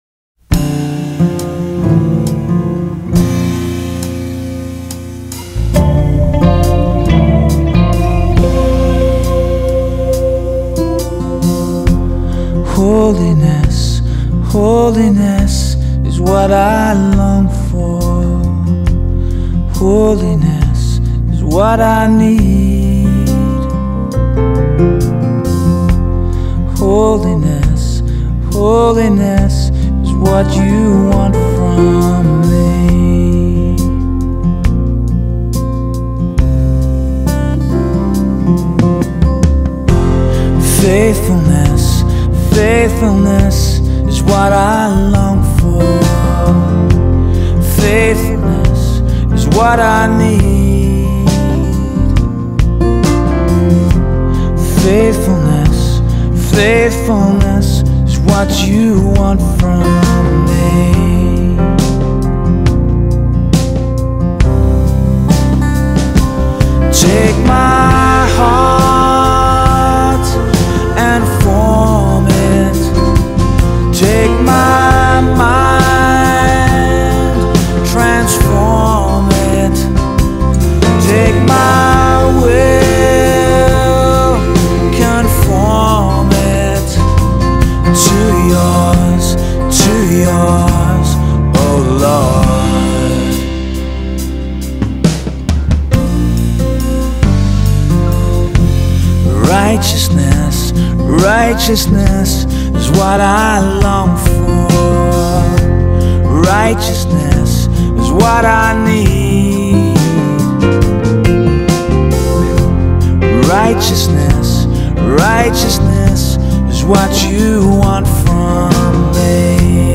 1. Sunday Worship – First Song: